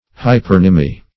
hypernymy - definition of hypernymy - synonyms, pronunciation, spelling from Free Dictionary